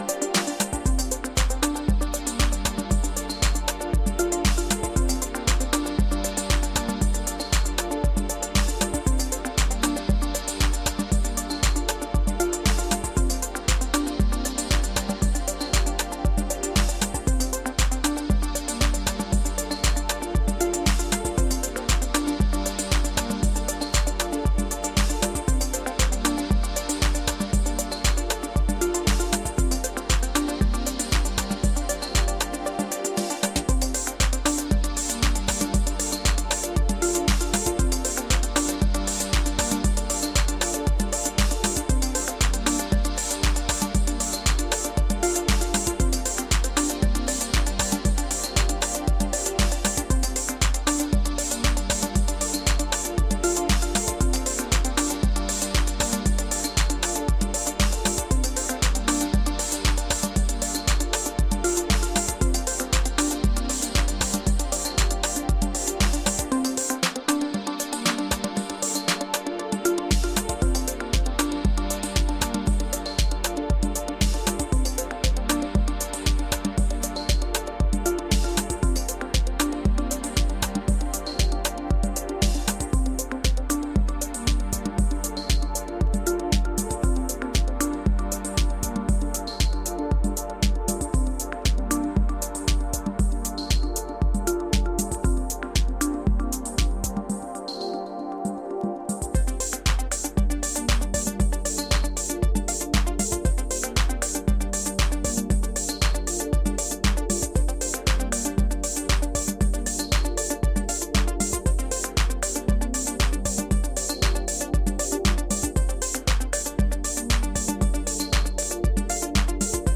Electronix House